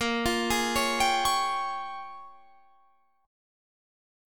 Bbm13 Chord
Listen to Bbm13 strummed